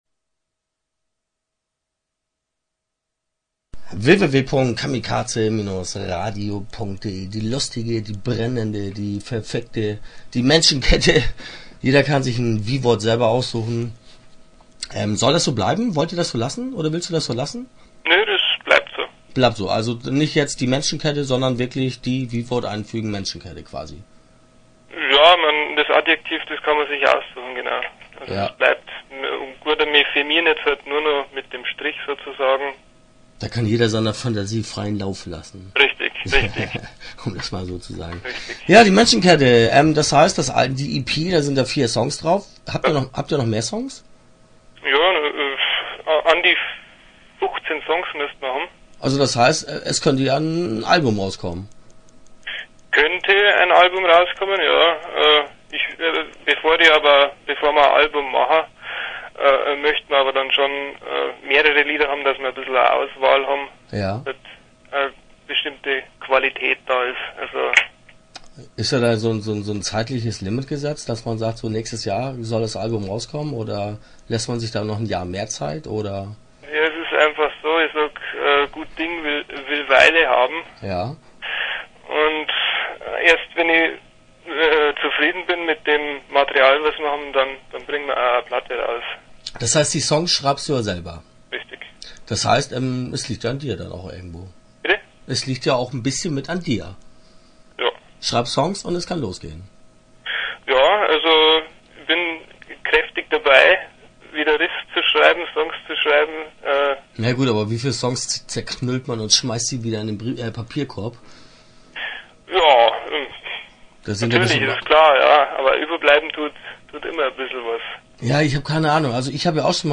Start » Interviews » Die Menschenkette